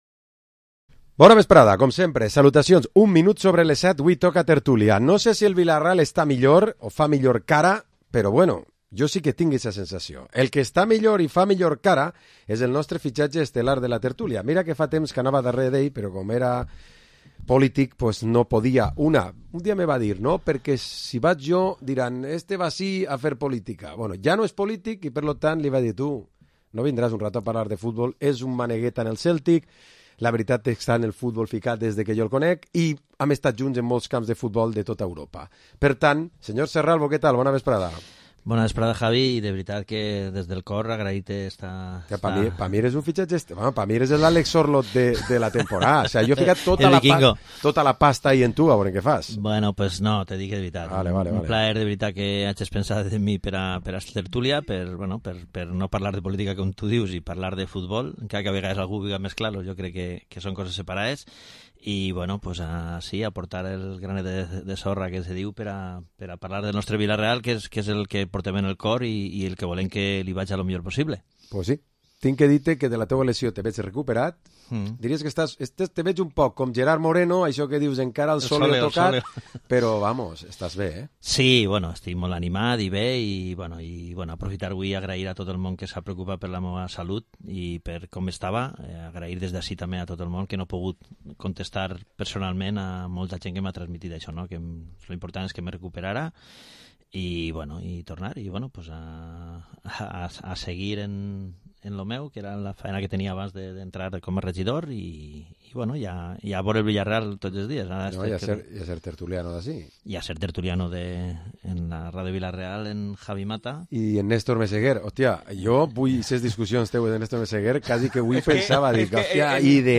Programa esports tertúlia dilluns 25 de Setembre